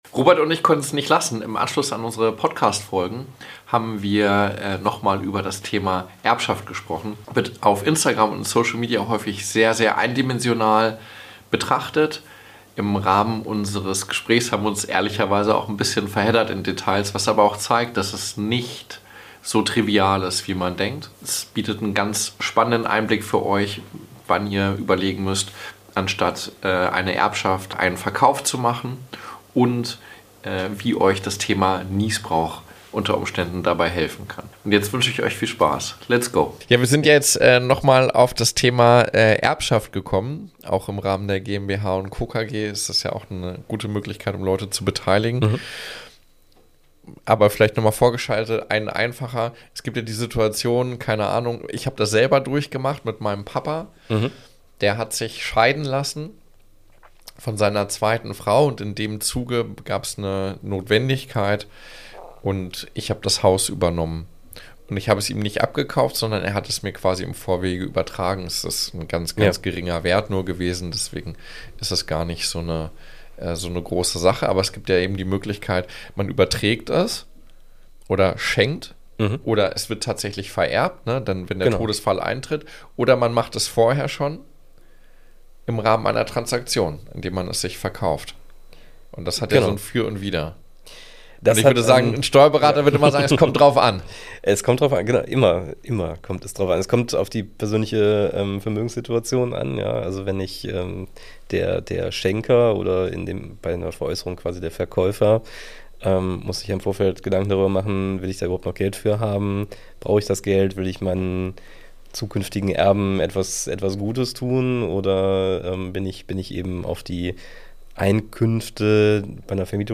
Dieses Gespräch ist ideal für alle, die Immobilien besitzen oder erben könnten – ganz egal ob Privatperson, Unternehmer oder künftiger Erbe.